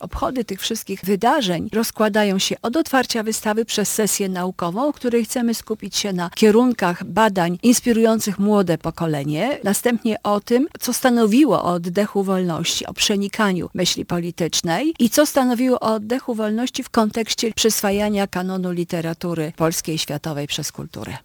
[Poranna Rozmowa] Lublin upamiętnia Jerzego Giedroycia – przed nami wystawy i sesje naukowe.